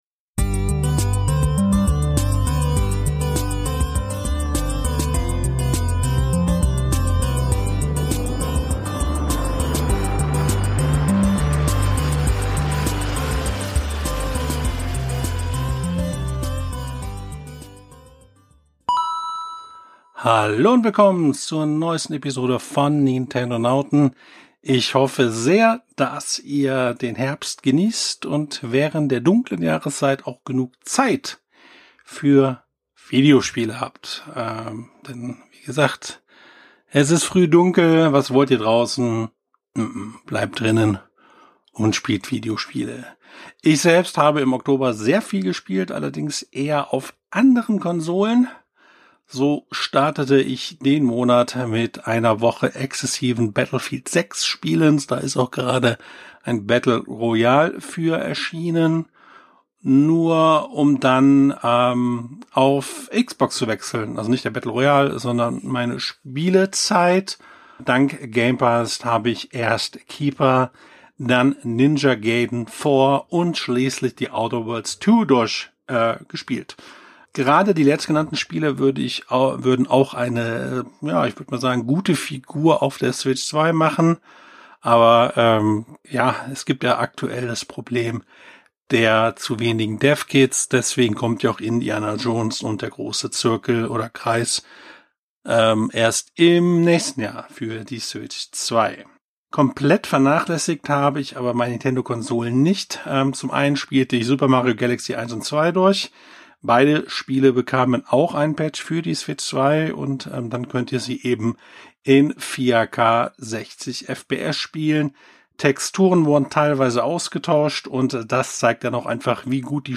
Er plaudert begeistert über „Pokémon Legenden Z-A“ und dessen neue Mechaniken, schwärmt von der Rückkehr von „Luigi’s Mansion“ auf der Switch 2 und erinnert sich an nostalgische GameCube-Zeiten. Außerdem gibt’s spannende Ausblicke auf kommende Nintendo-Highlights wie Animal Crossing: New Horizons.